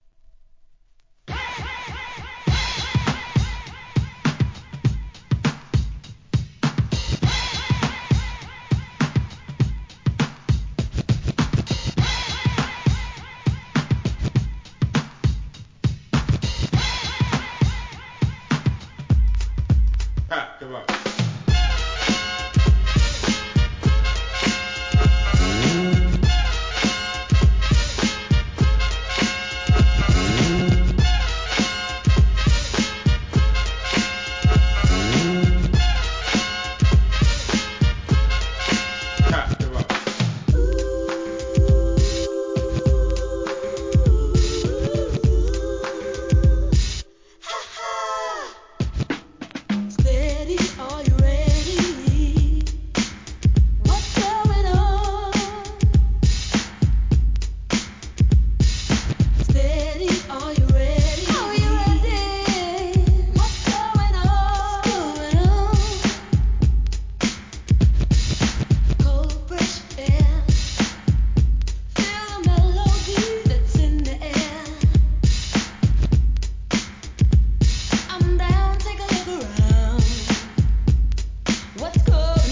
HIP HOP/R&B
DJのために繋ぎ易さも考慮されたREMIX人気シリーズ59番!!